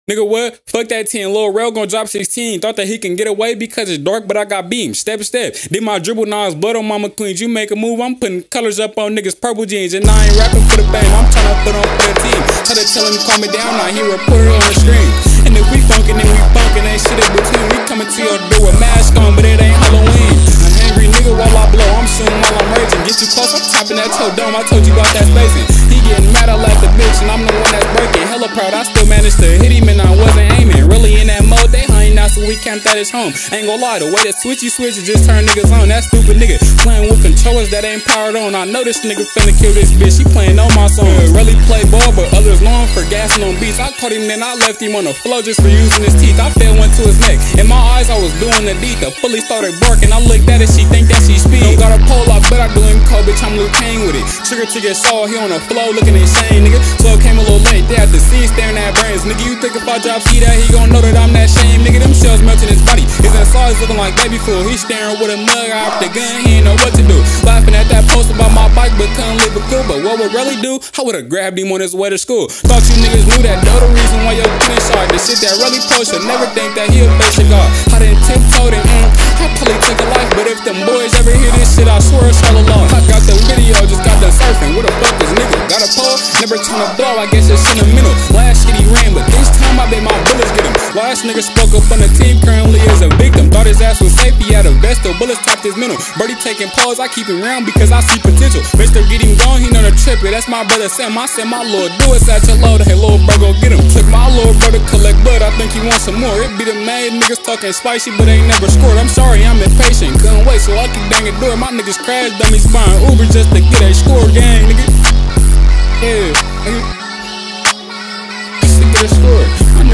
привнося в свою музыку элементы хип-хопа и R&B